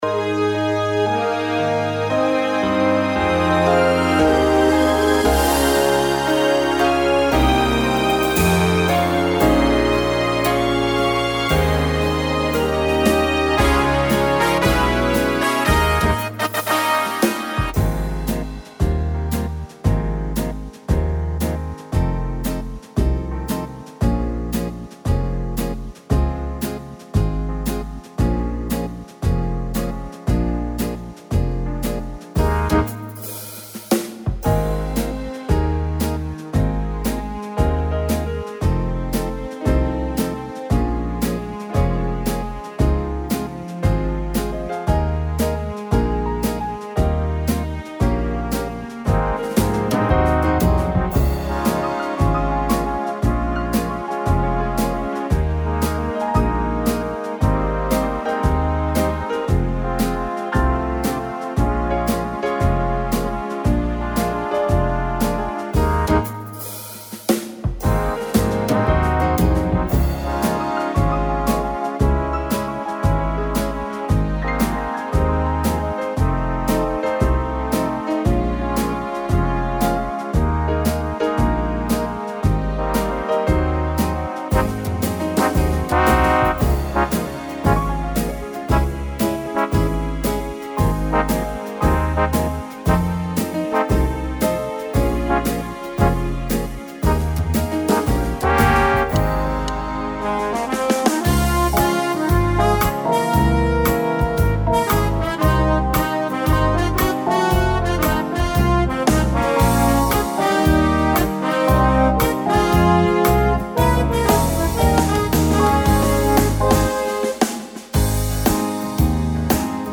Tone Tốp (G#)
•   Beat  01.